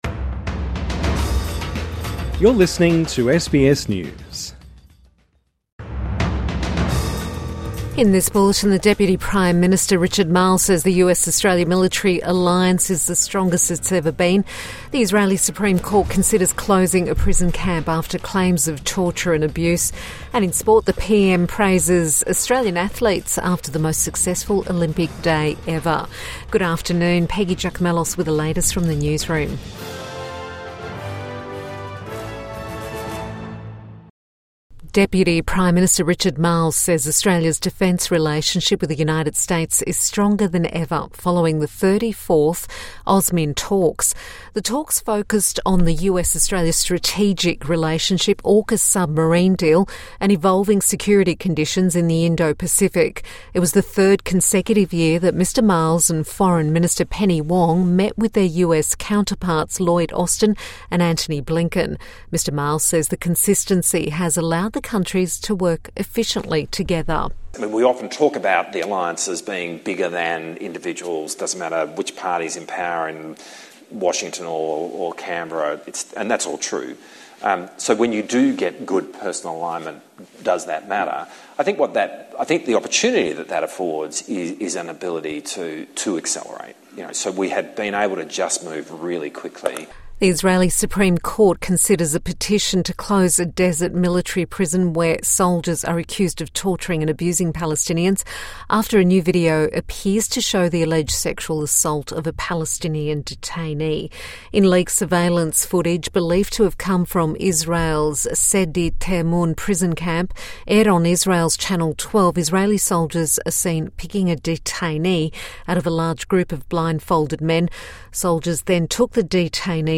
Evening News Bulletin 8 August 2024